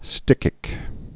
(stĭkĭk)